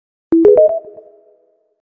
Звуки подключения зарядки